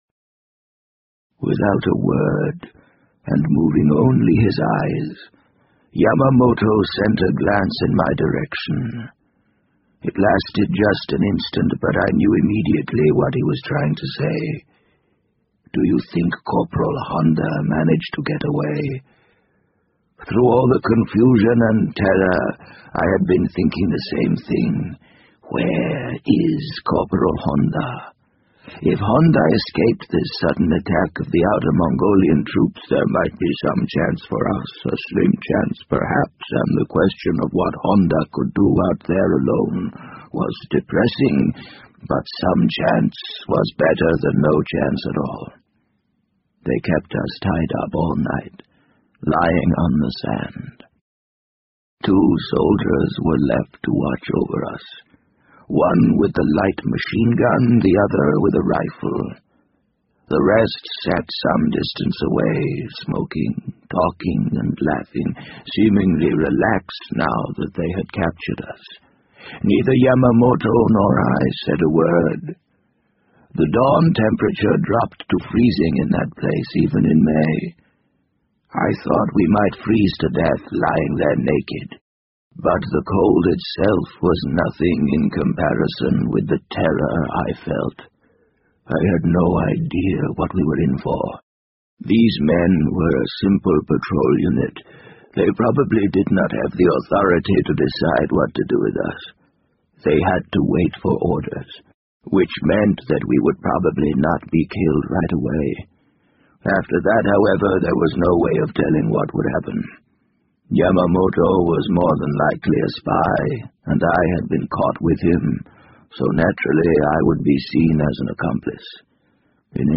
BBC英文广播剧在线听 The Wind Up Bird 004 - 15 听力文件下载—在线英语听力室